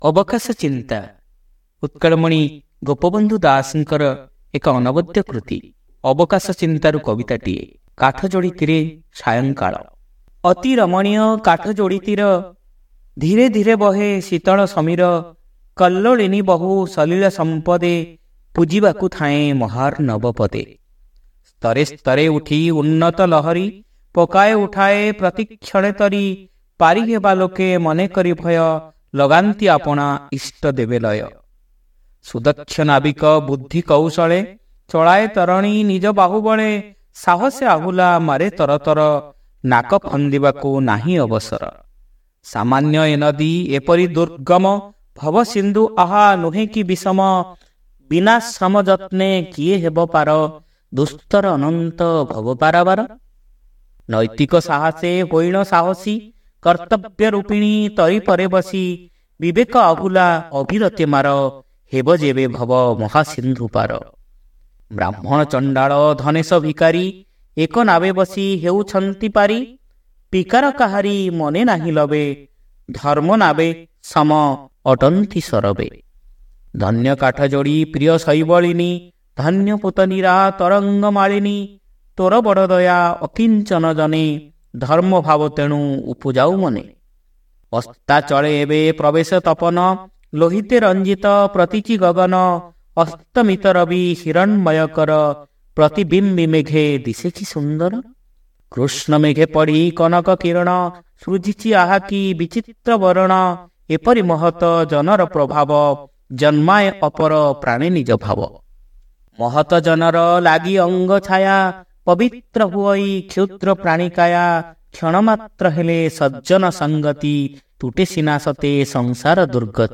Audio Poems : Kathajoditire Sayangkala